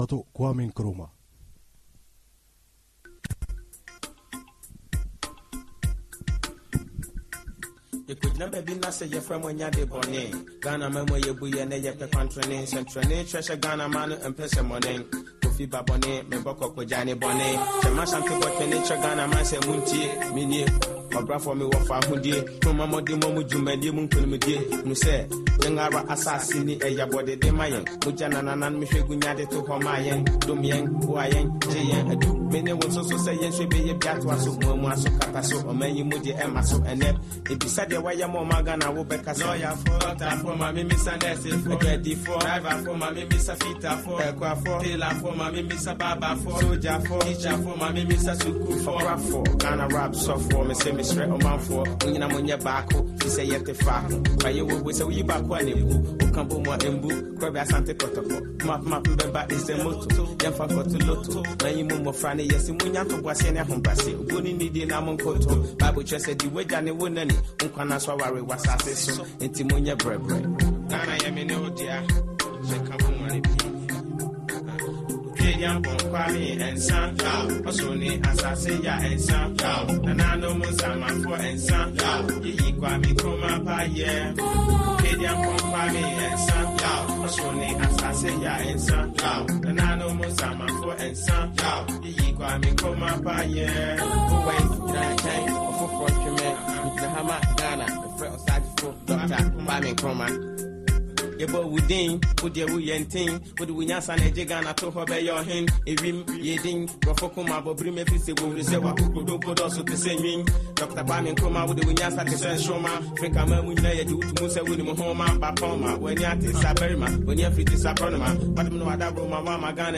La sua intransigenza verso le potenze occidentali e il suo avvicinamento all’Unione Sovietica e alla Cina fecero sì che venisse rovesciato da un colpo di stato militare nel 1966, mentre si trovava in Vietnam. Ascolta l’approfondimento, preceduto da un pezzo musicale di un gruppo ghanese dedicato proprio a Nkrumah: